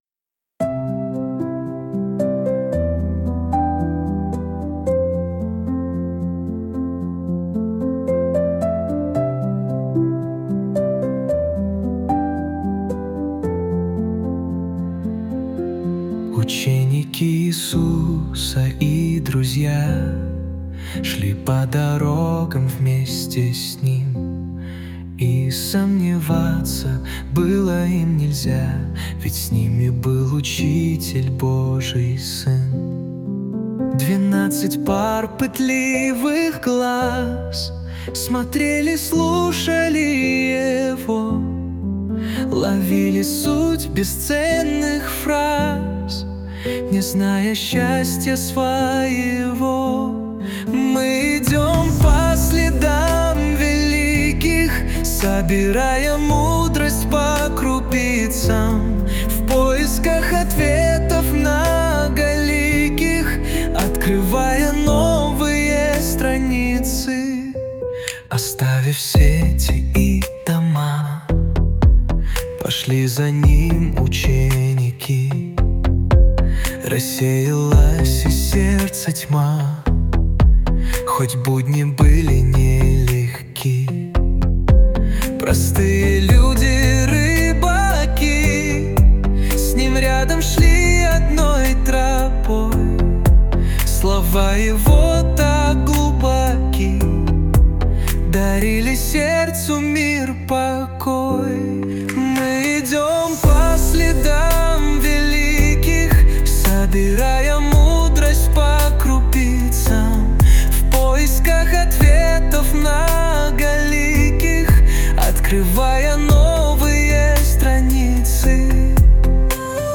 песня ai
170 просмотров 842 прослушивания 38 скачиваний BPM: 113